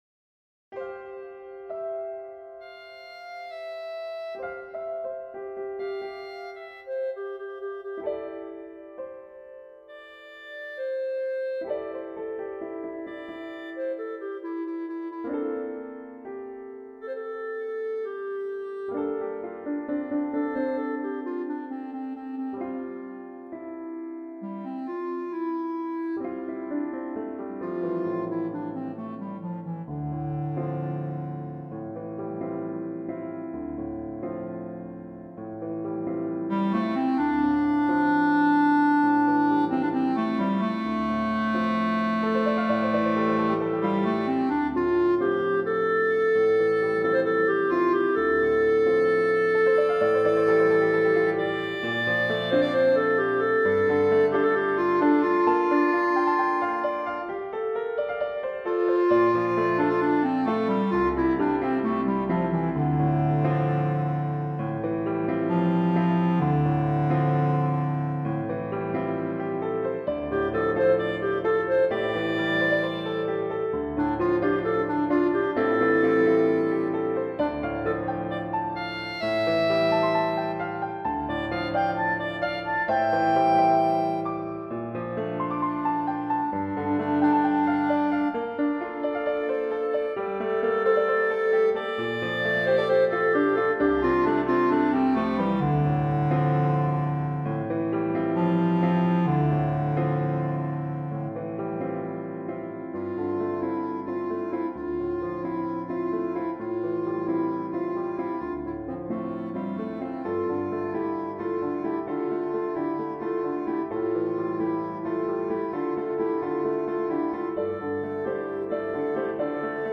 for Clarinet and Piano (2021)